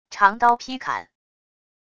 长刀劈砍wav音频